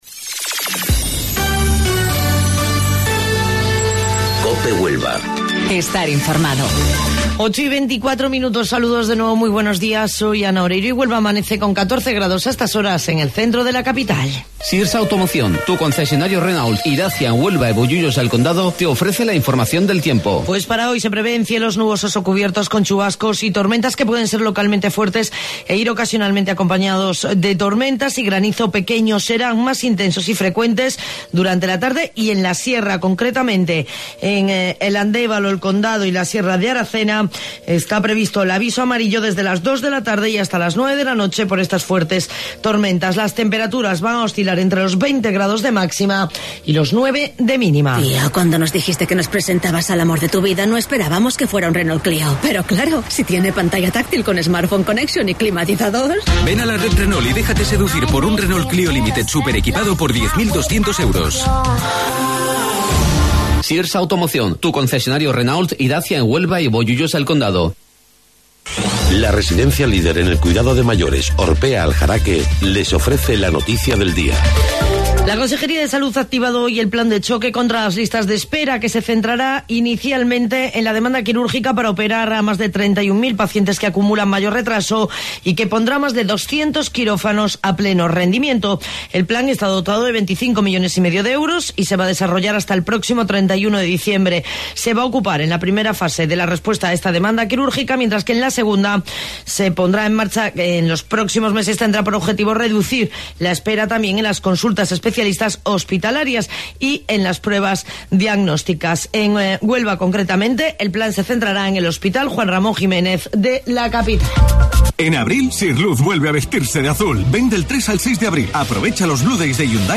AUDIO: Informativo Local 08:25 del 1 de Abril